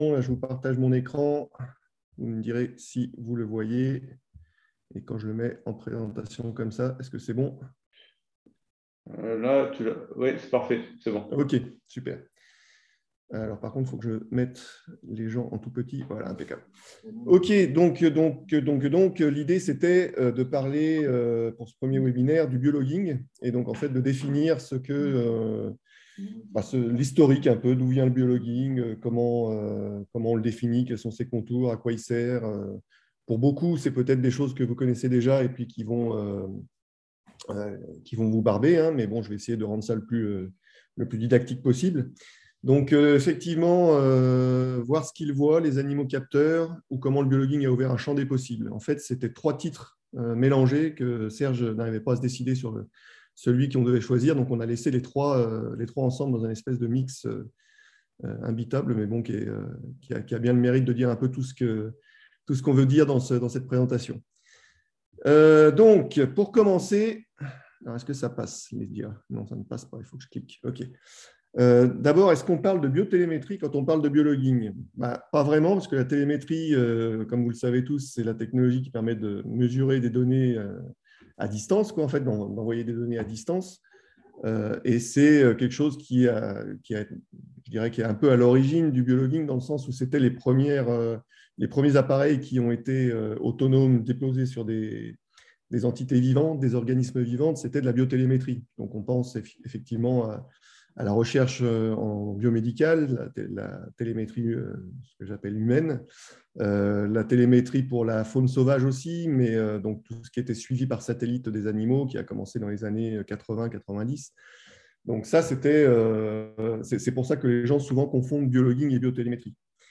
Premier webinaire du GT NO3 (Nouveaux Outils de l'Observation de l'Océan).